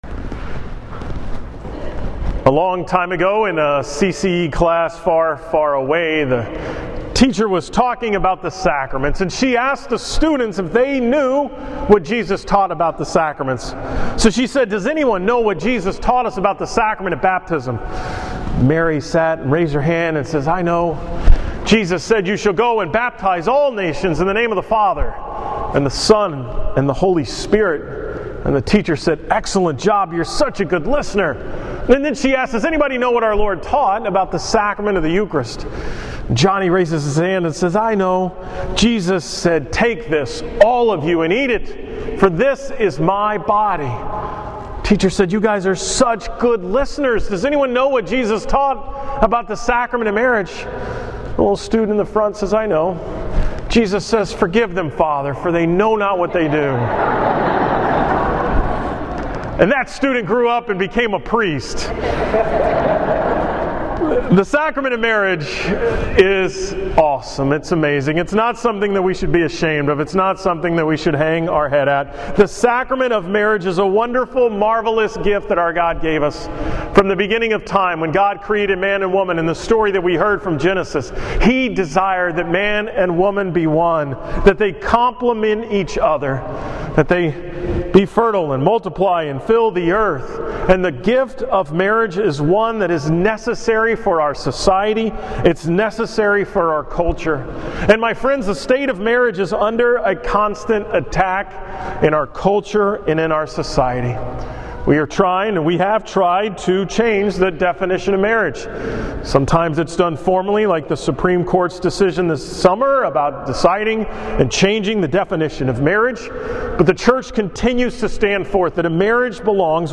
From the 10 am Mass at St. Ambrose on Sunday, October 4, 2015